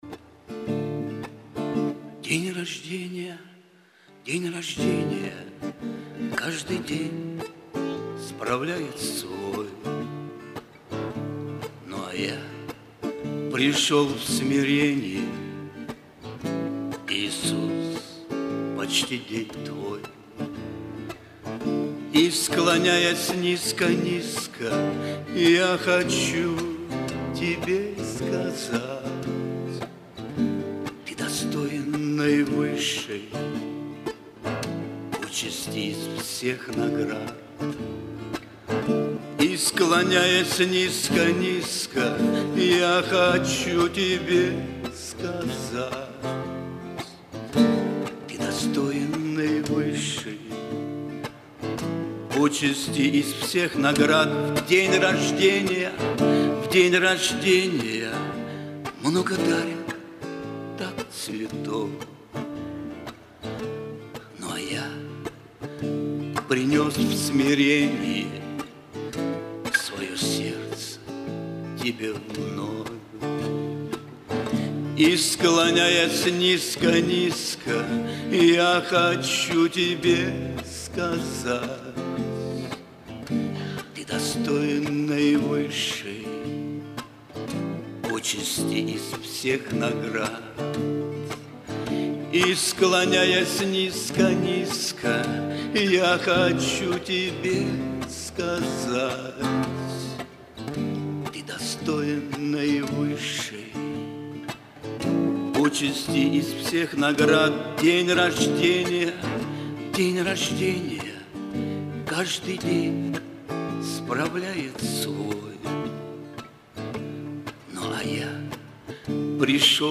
Богослужение 04.01.2023
Пение